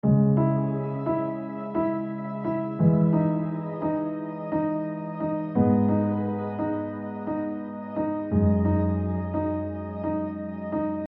ZODIAC: DRUM & BASS
Zodara_Kit_G#min - Chords
Antidote_Zodara_Kit_Gmin-Chords.mp3